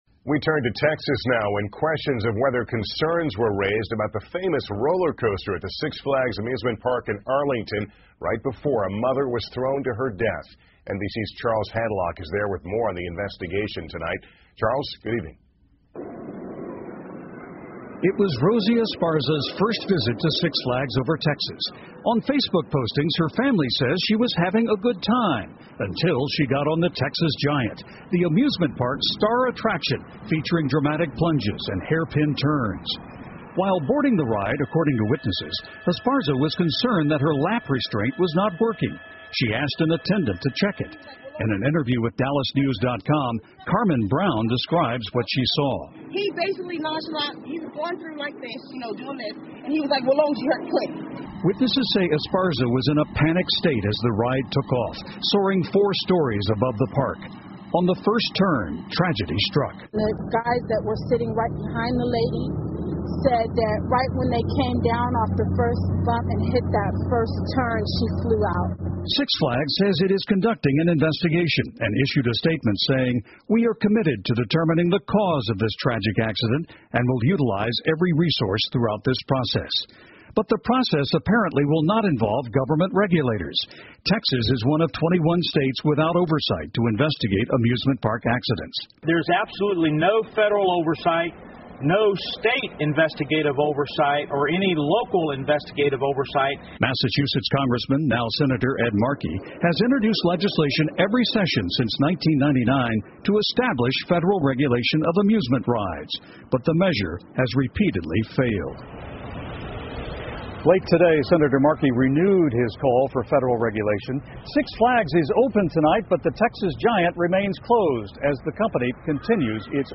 NBC晚间新闻 过山车死亡事件引热议 听力文件下载—在线英语听力室